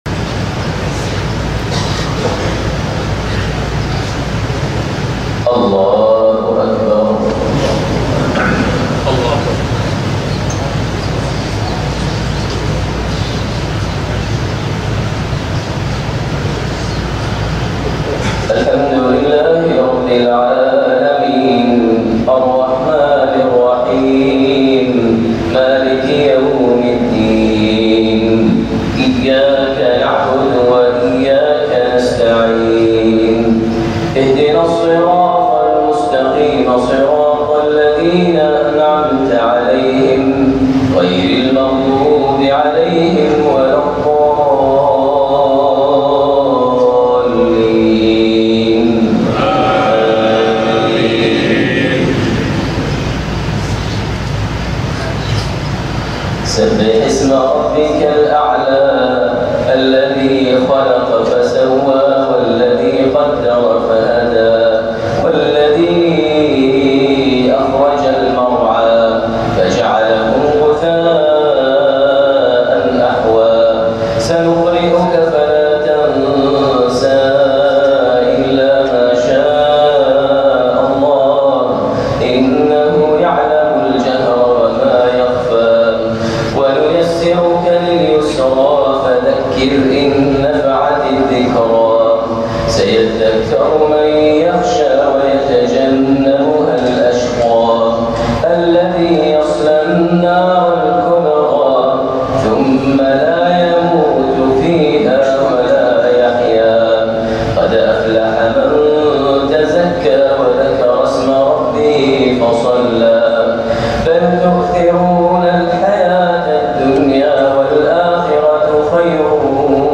صلاة الجمعة من جامع كاولون الكبير ١٥ رجب ١٤٣٧هـ سورتي الأعلى / الغاشية > زيارة الشيخ ماهر المعيقلي لولاية هونج كونج ١٤٣٧هـ > المزيد - تلاوات ماهر المعيقلي